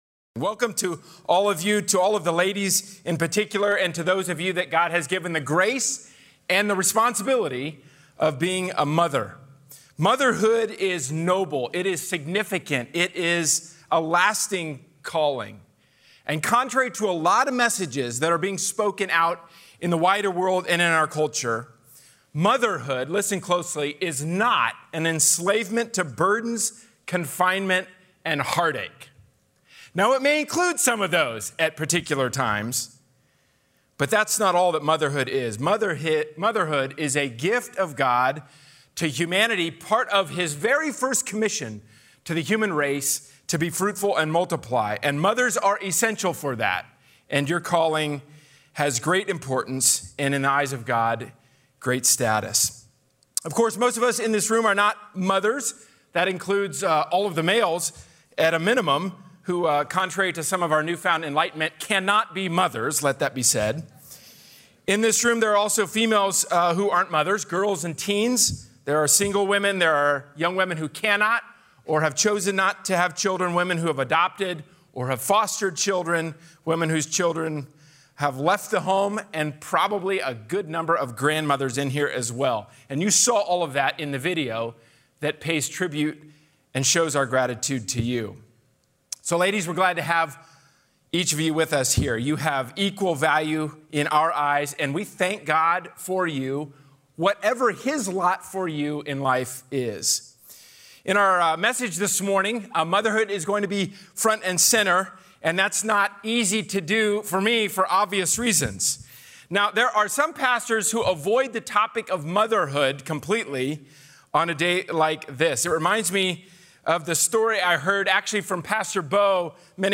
A sermon from the series "Standalone Sermons."